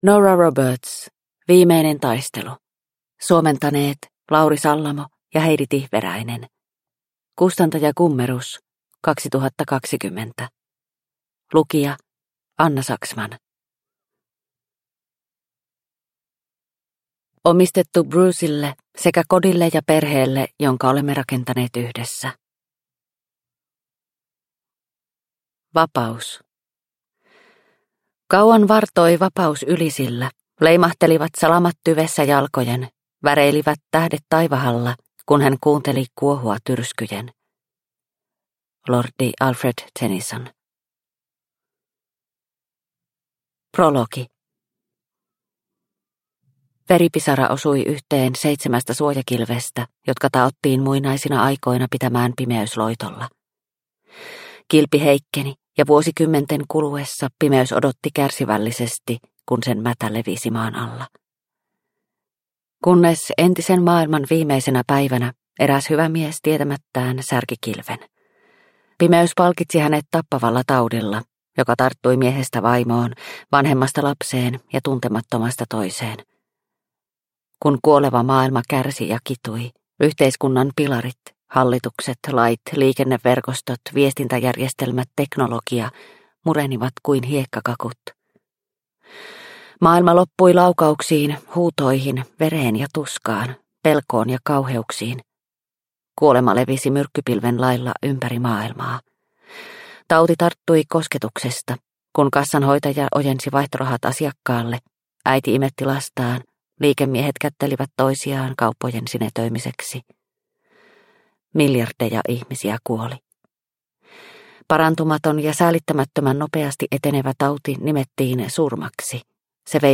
Viimeinen taistelu – Ljudbok – Laddas ner